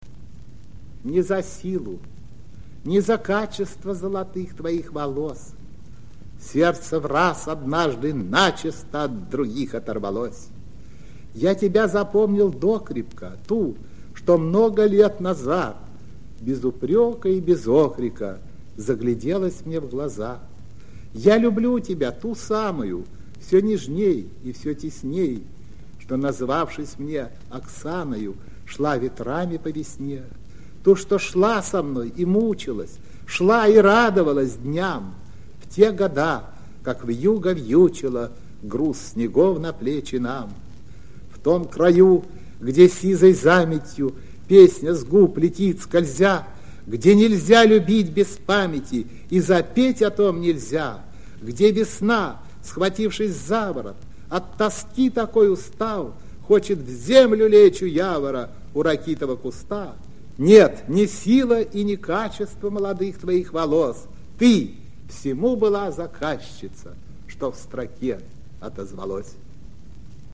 1. «Николай Асеев – Не за силу, не за качество… (читает автор)» /
Aseev-Ne-za-silu-ne-za-kachestvo.-chitaet-avtor-stih-club-ru.mp3